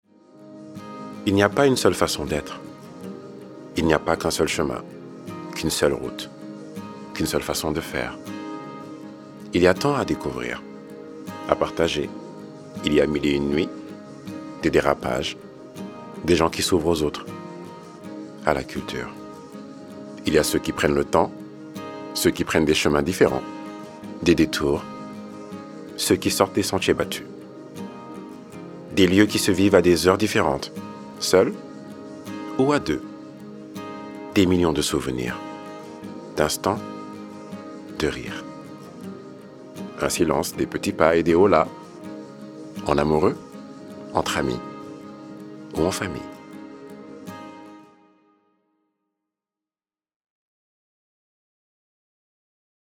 Voix-off
20 - 40 ans - Baryton-basse